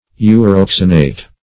uroxanate - definition of uroxanate - synonyms, pronunciation, spelling from Free Dictionary Search Result for " uroxanate" : The Collaborative International Dictionary of English v.0.48: Uroxanate \U*rox"a*nate\, n. (Chem.) A salt of uroxanic acid.